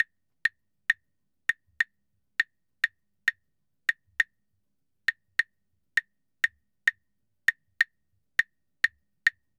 Claves_Salsa 100_1.wav